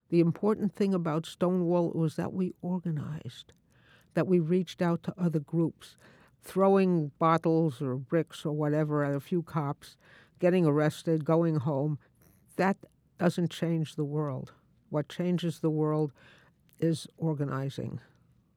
Here is a short excerpt of the interview: